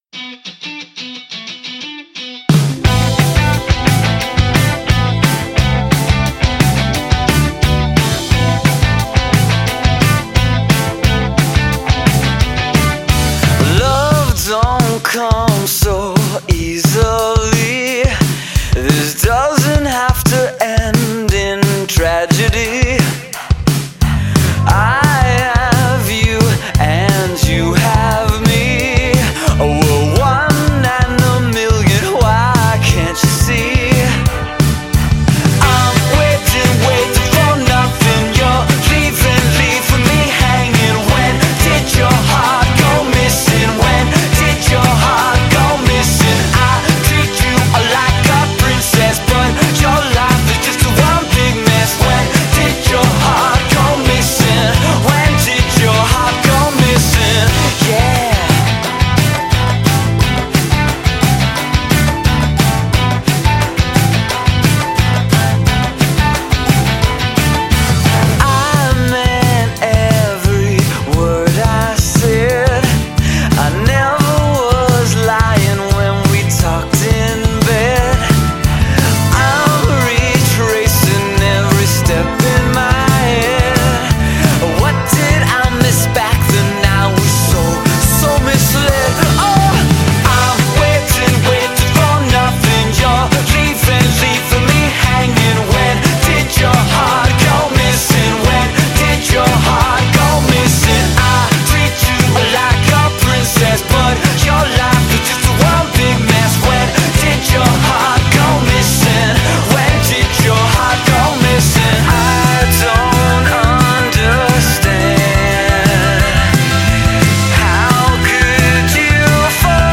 LA power pop quintet
evokes a lot of great pop from a lot of eras.